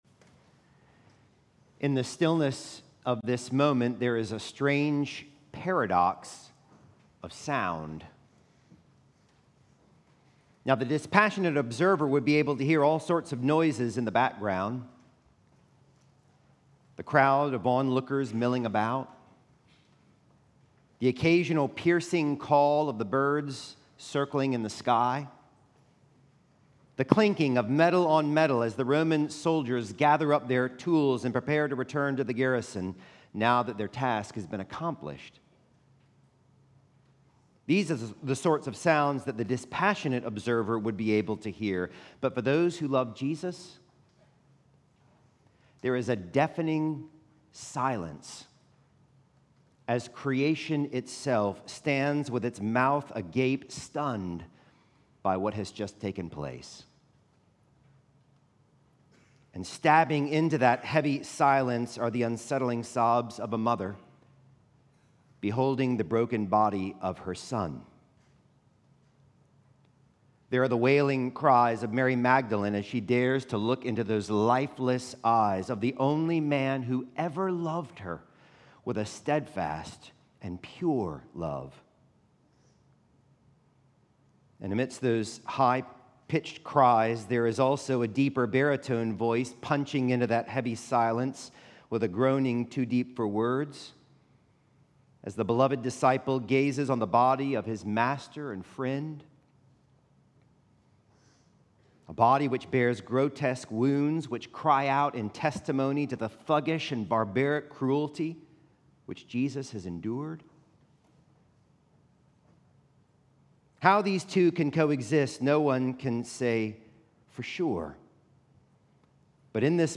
April 18, 2025 ~ Good Friday Liturgy